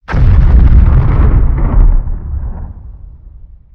probeboom.wav